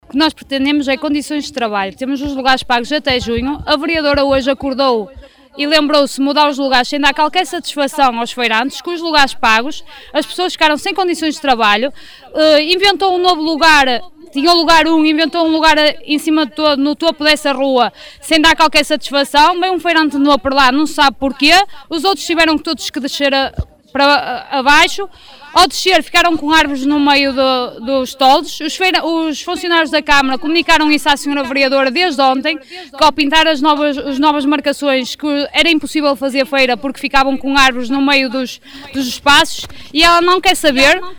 falou à Rádio Cávado sobre a manifestação de ontem.